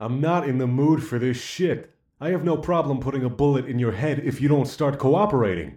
EscortPissedOff2.ogg